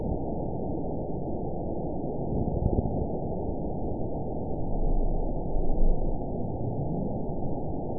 event 917852 date 04/19/23 time 01:54:07 GMT (1 year ago) score 9.48 location TSS-AB10 detected by nrw target species NRW annotations +NRW Spectrogram: Frequency (kHz) vs. Time (s) audio not available .wav